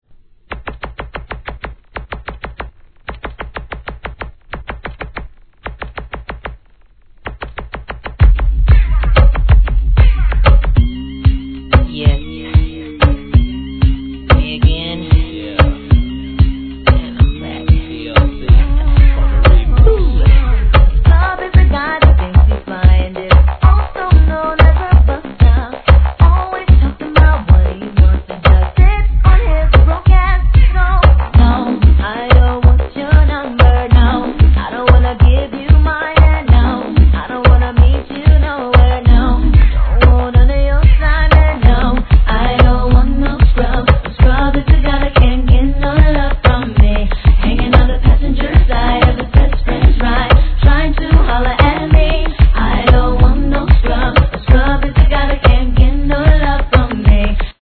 REGGAE X HIP HOP/R&B ブレンドREMIXシリーズ